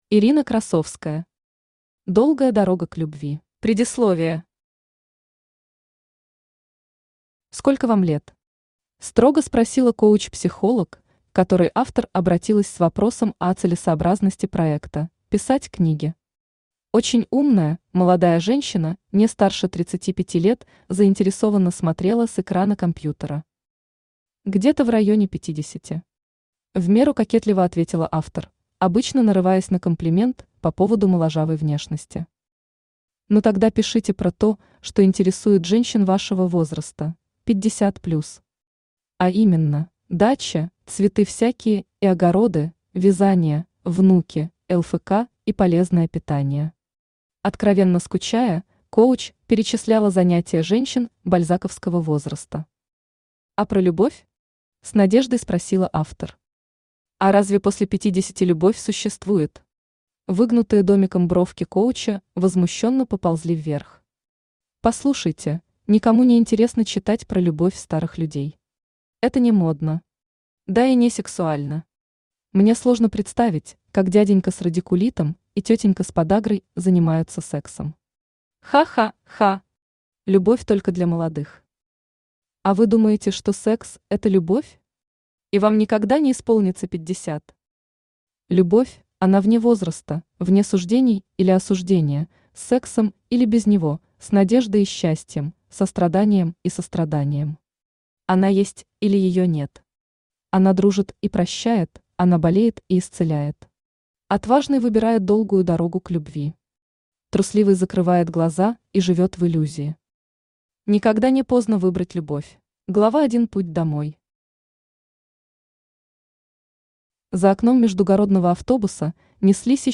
Аудиокнига Долгая дорога к любви | Библиотека аудиокниг
Aудиокнига Долгая дорога к любви Автор Ирина Красовская Читает аудиокнигу Авточтец ЛитРес.